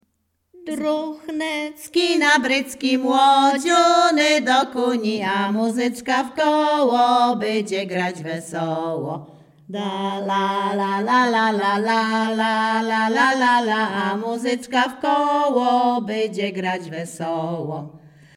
Śpiewaczki z Chojnego
województwo łódzkie, powiat sieradzki, gmina Sieradz, wieś Chojne
Weselna
na wyjazd do kościoła miłosne weselne wesele przyśpiewki